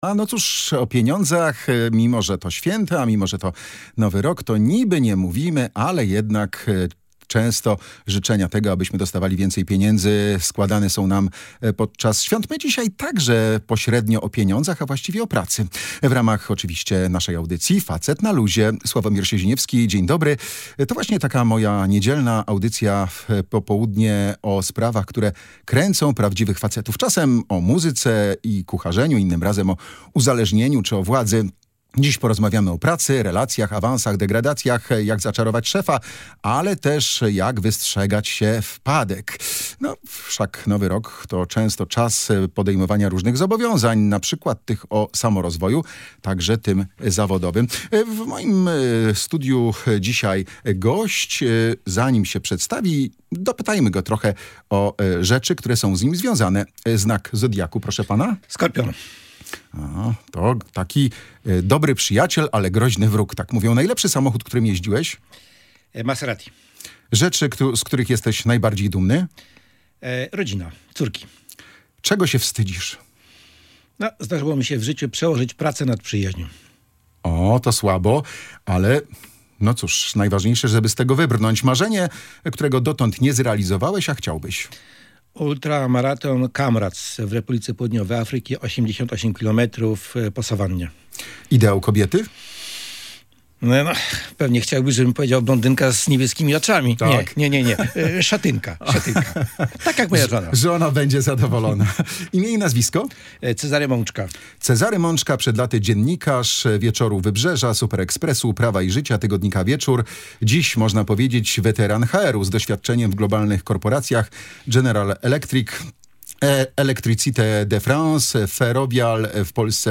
Panowie rozmawiali o sposobach na podwyżkę, toksycznych szefach, benefitach i pracownikach zza granicy.